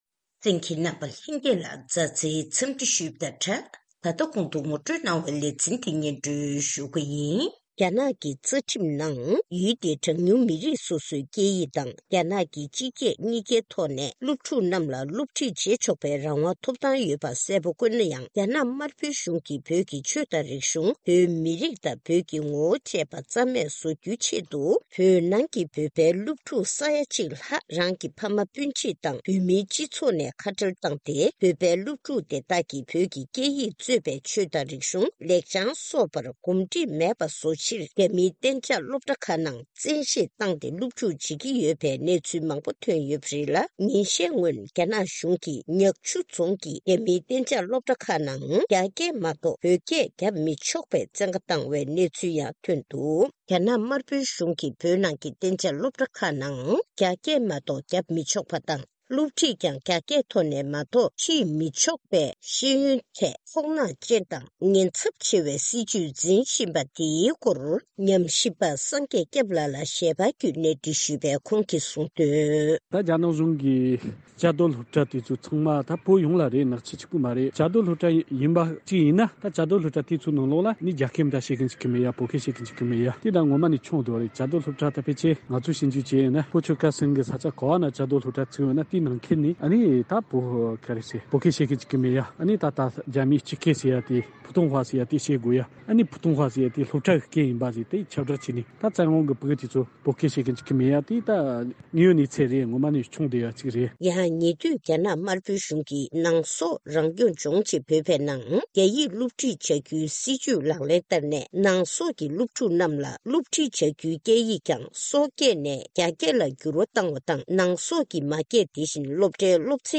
གནས་འདྲིའི་ལེ་ཚན་